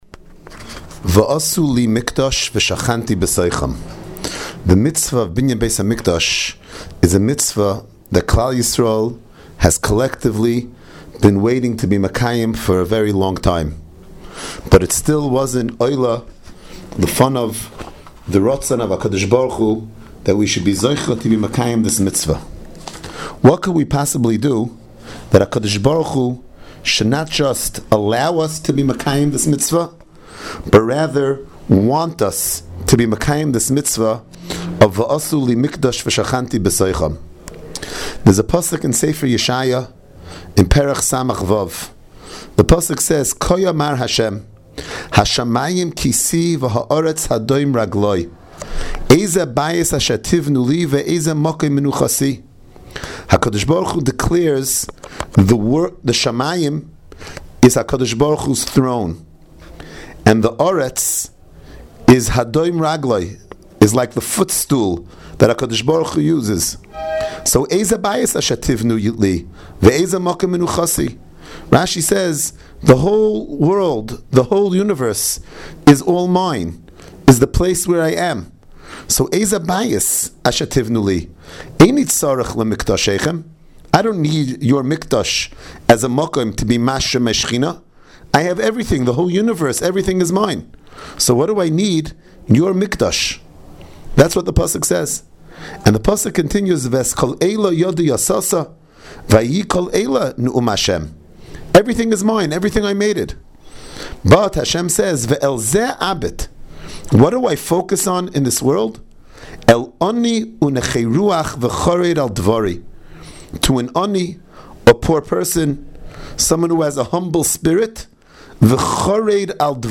Inspiring Divrei Torah, Shiurim and halacha on Parshas Teruma from the past and present Rebbeim of Yeshivas Mir Yerushalayim.